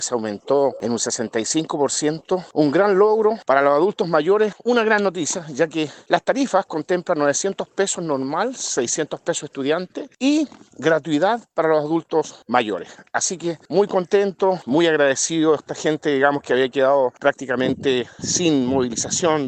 Una buena noticia, especialmente para los adultos mayores, quienes viajaran gratuitamente. Según lo señalado por el concejal de Corral, Fernando Villagrán.